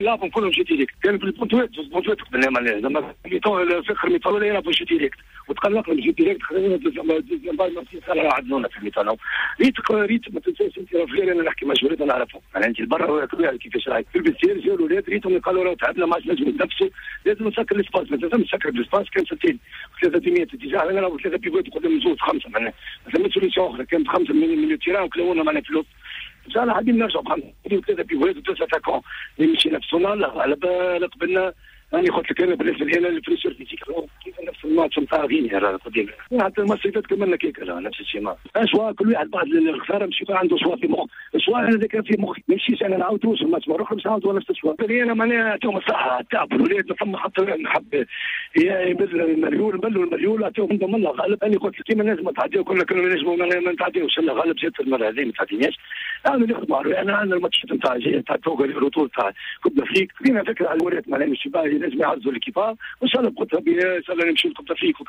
L'entraîneur de la sélection tunisienne de football, Hatem Missaoui, a réagi, au micro de Jawhara Fm, à l'élimination des Aigles de Carthage des quarts de finale du Championnat d'Afrique des Nations (CHAN 2016), face à la modeste formation malienne.